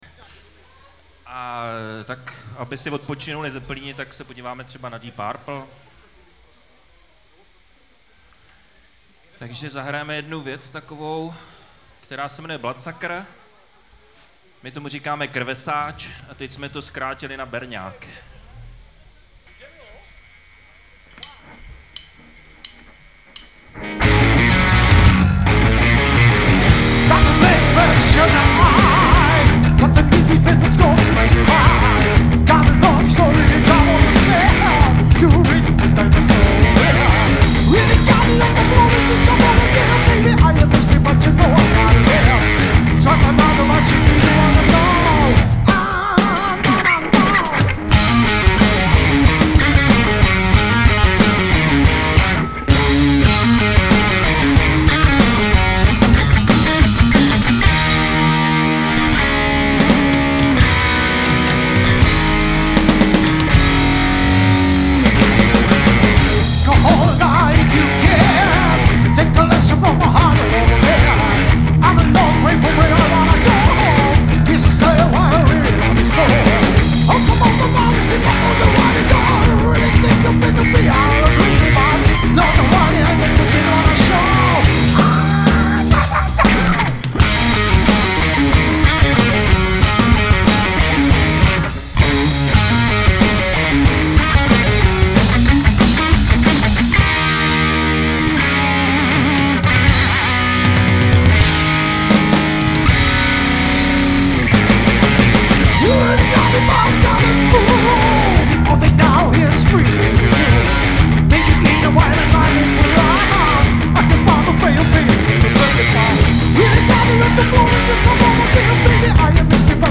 MP3 - Klub Kino Černošice (záznam koncertu)
zpěv, kytary
baskytara, zpěv
bicí, zpěv